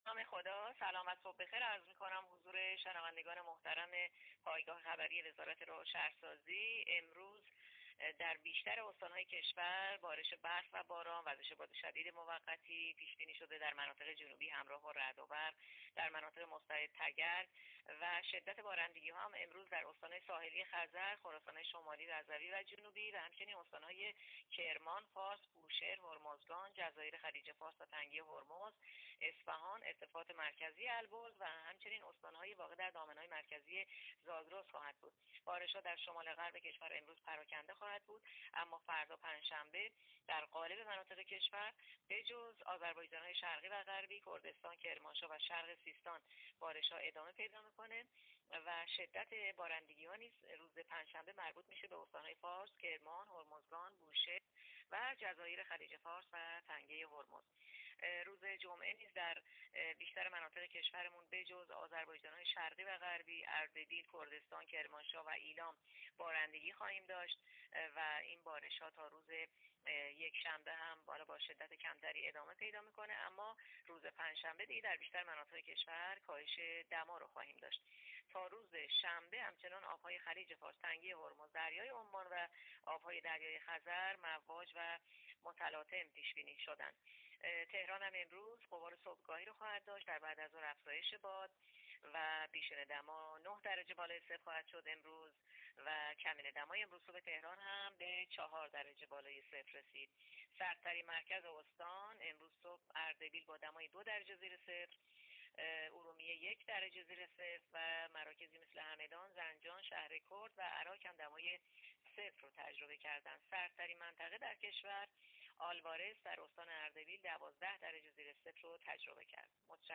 گزارش رادیو اینترنتی پایگاه‌ خبری از آخرین وضعیت آب‌وهوای ۲۶ آذر؛